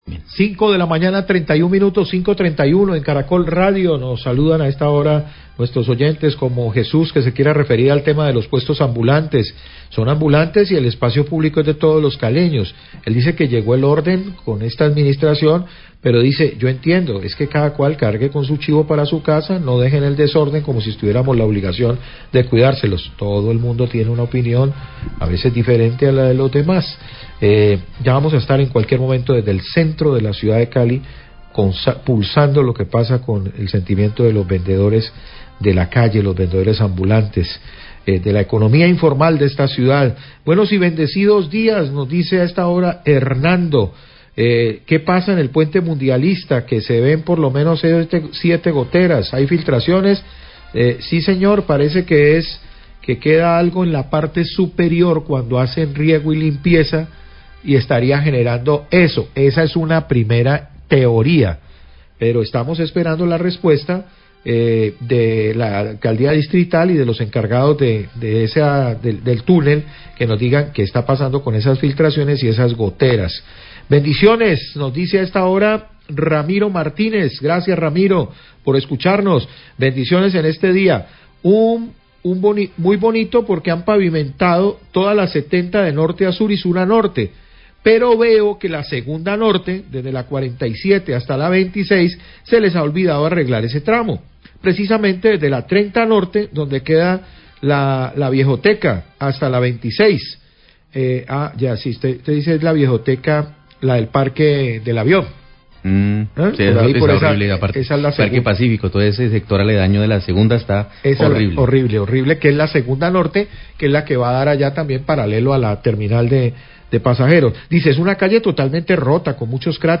Radio
Periodista lee mensajes de whatsapp enviados al noticiero donde los oyentes informan sobre situaciones en vías de la ciudad como, por ejemplo, situación vendedores ambulantes Plaza de Caycedo; goteras en el tunel mundialista; falta de pavimentación avenida 2da norte.